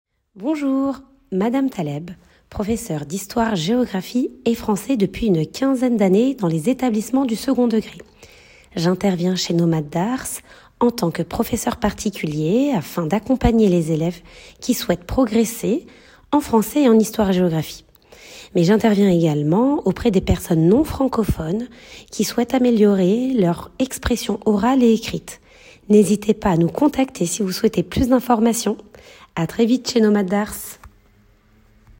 Les professeurs de français vous parlent!